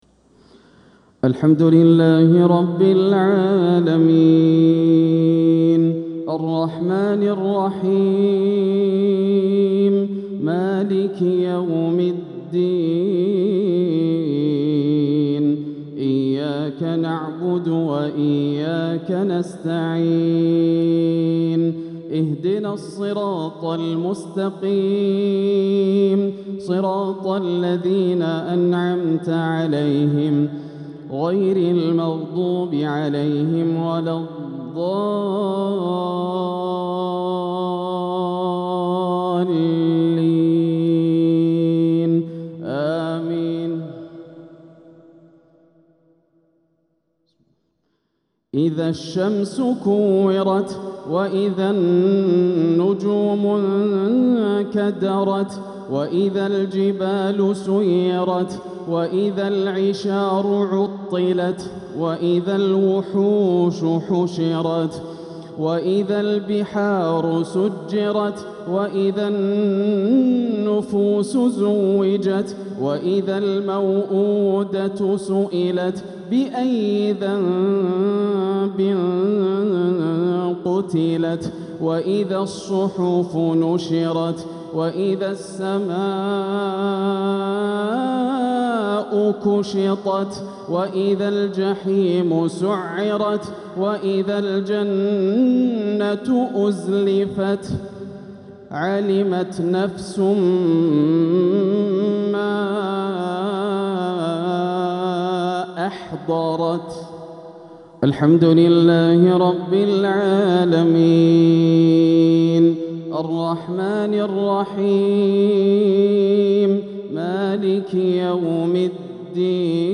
ترتيل بديع لسورة التكوير | مغرب الأربعاء 5-6-1447هـ > عام 1447 > الفروض - تلاوات ياسر الدوسري